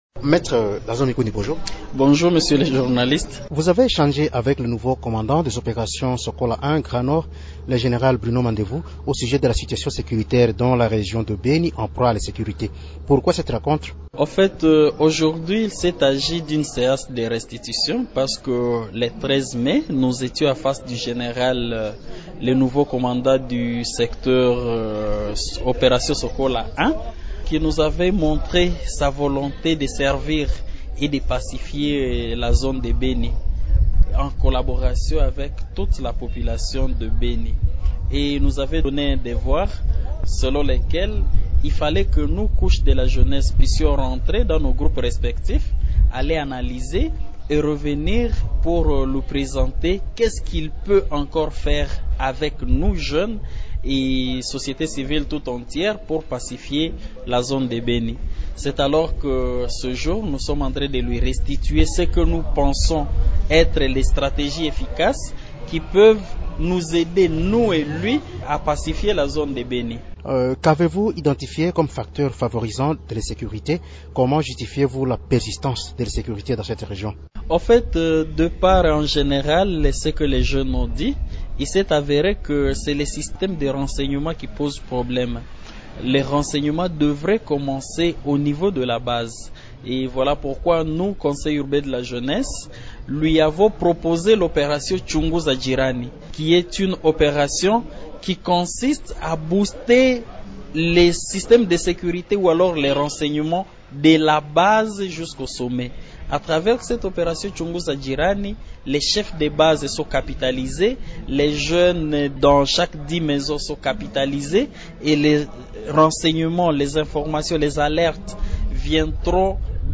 Invité de Radio Okapi, cet avocat explique qu’il s’agit d’un système de renseignement qui partirait de la base.